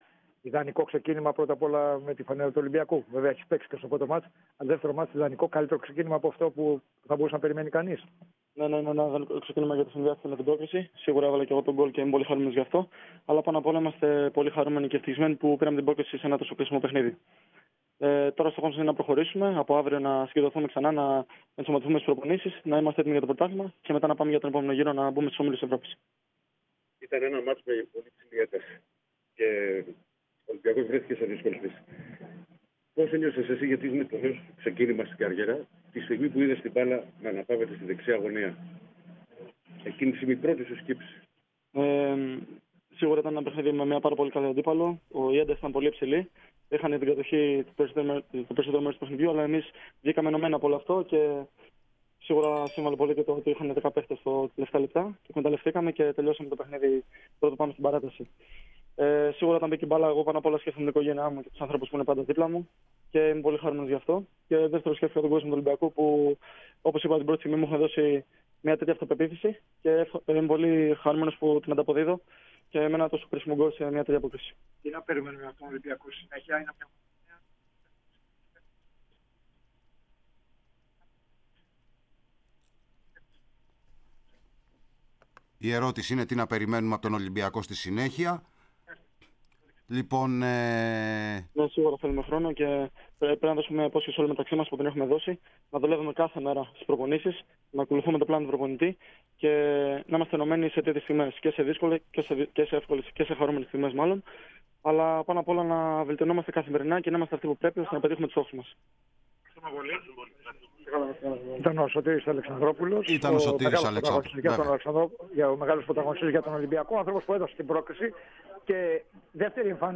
O χρυσός σκόρερ των “ερυθρόλευκων” που με το τέρμα του στο 90’+5′ χάρισε την πρόκριση στην ομάδα του, μίλησε στους εκπροσώπους Τύπου αμέσως μετά τη λήξη της αναμέτρησης και σχολίασε το ιδανικό του ξεκίνημα που συνδύασε με γκολ σε ένα τόσο κρίσιμο παιχνίδι.